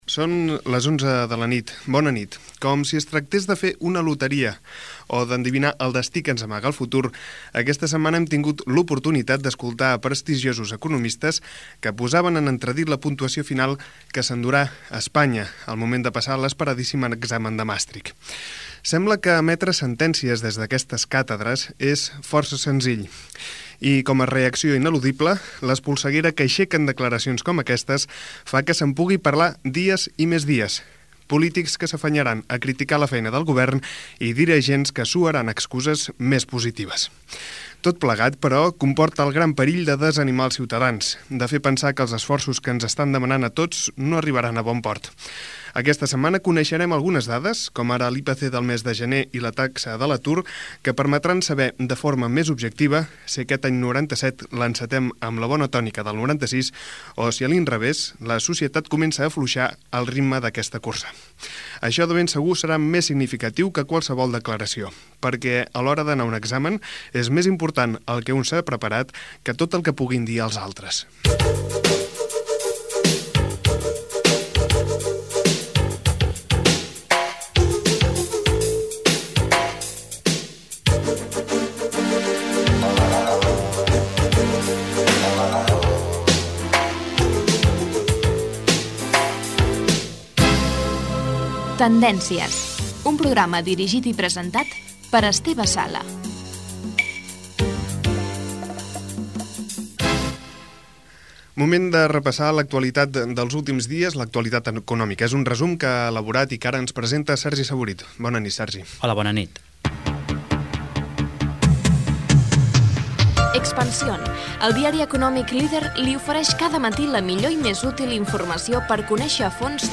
Hora, comentari sobre l'economia de l'inici de l'any 1997, careta, resum de l'actualitat econòmica (declaracions del ministre d'economia espanyol Abel Matutes)
Informatiu